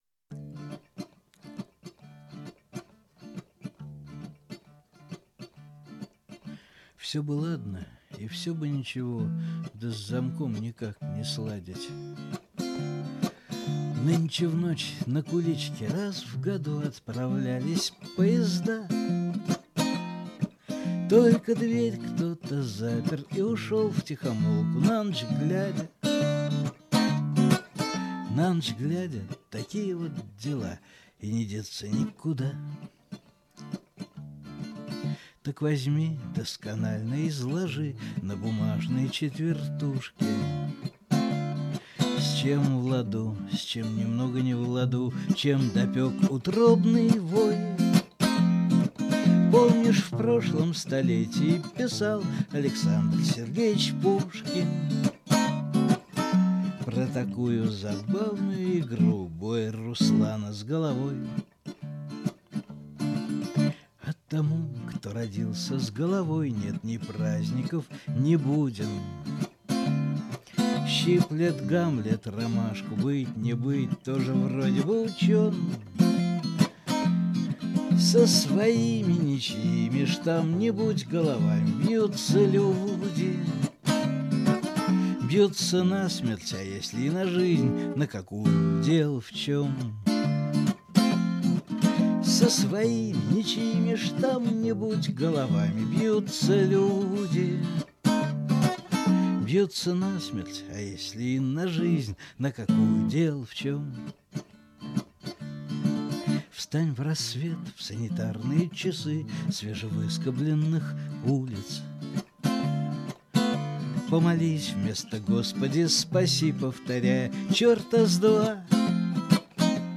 В исполнении автора